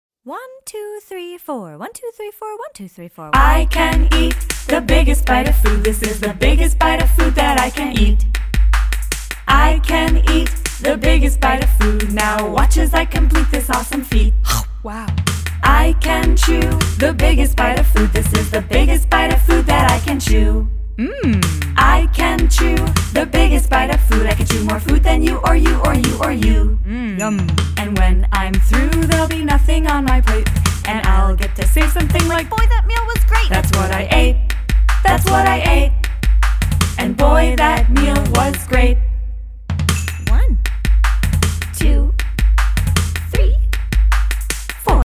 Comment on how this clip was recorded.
All tracks except Radio Edits include scripted dialogue.